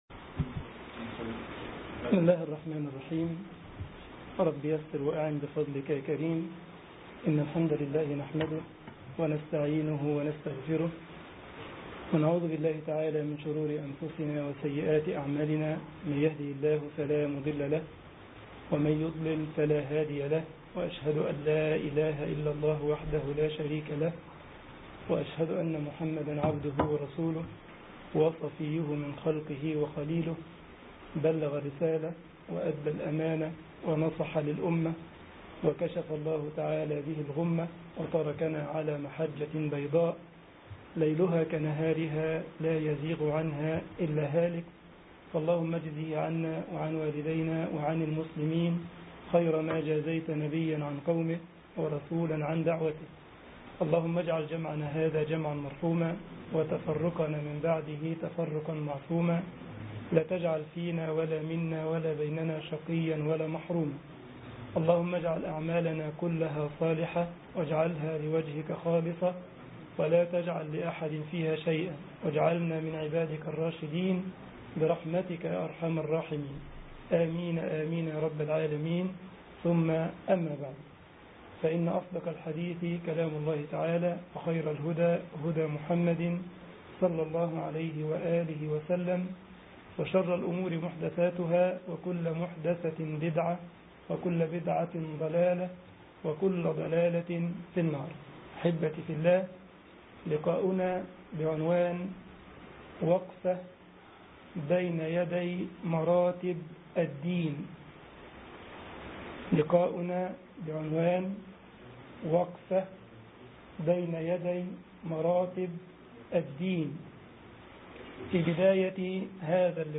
درس
مسجد عثمان بن عفان - لودفيغسهافن ـ ألمانيا